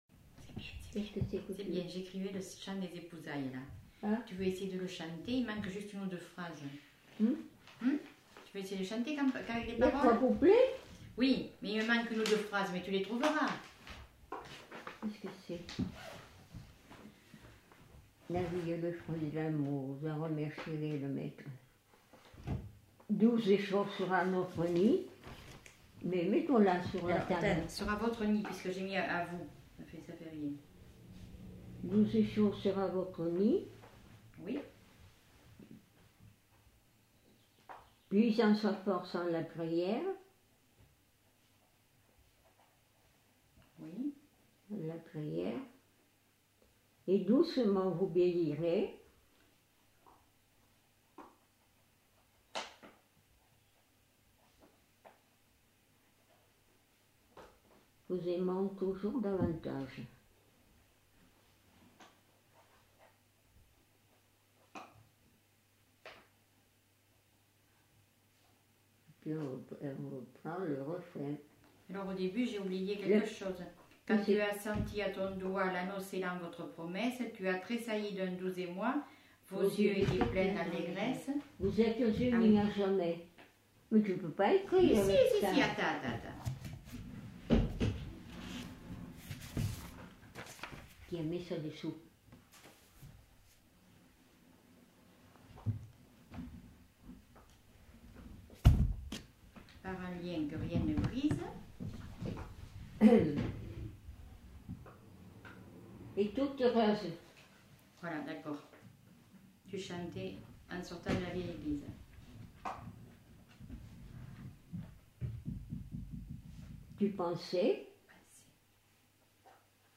Aire culturelle : Quercy
Genre : chanson-musique
Type de voix : voix de femme
Production du son : chanté
Instrument de musique : piano
Description de l'item : fragment ; 2 c. ; refr.
Notes consultables : L'une des femmes dicte les paroles de la chanson à l'autre en début de séquence. L'informatrice fait des efforts pour se remémorer le chant.